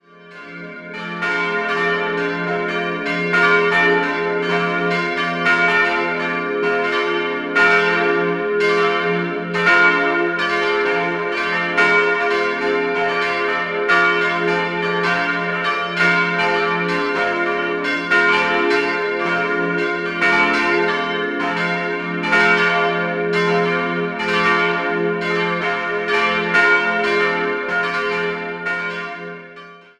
4-stimmiges Wachet-auf-Geläut: f'-a'-c''-d''